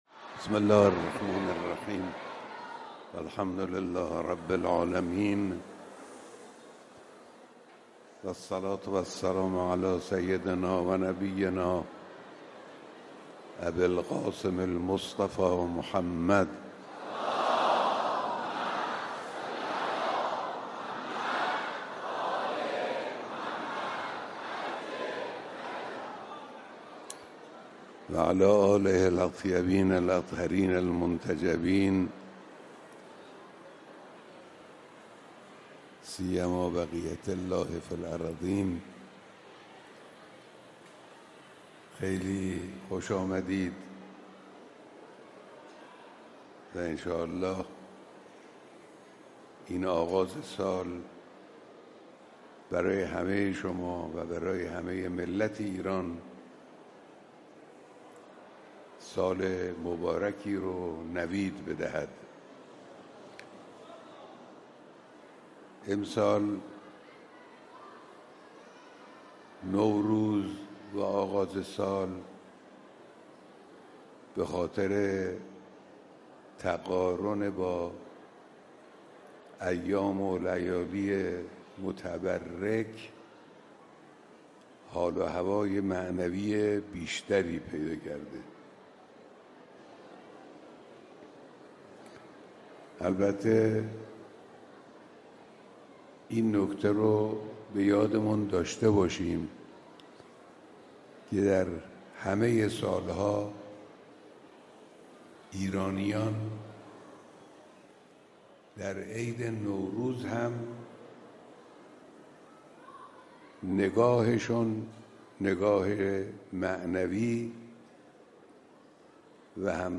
فیلم و صوت کامل سخنرانی نوروزی رهبر انقلاب در جمع اقشار مختلف مردم
اقشار مختلف مردم امروز اول فروردین ۱۴۰۴ در آغاز سال جدید با رهبر معظم انقلاب در حسینیه امام خمینی(ره) دیدار کردند.